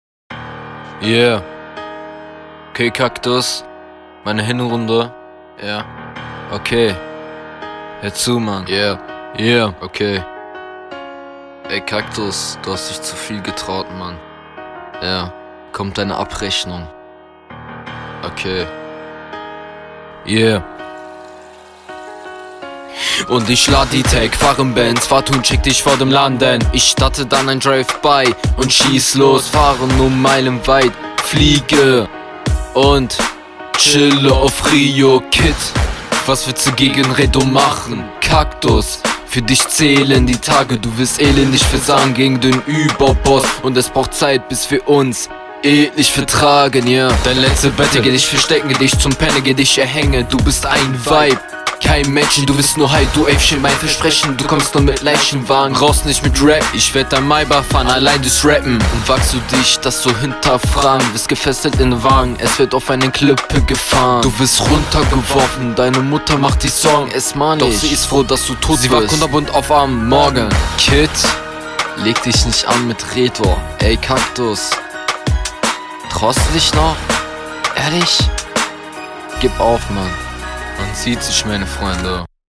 Naja. Klingt etwas besser als die RR1, aber das ist immer noch durchweg schwach gerappt. …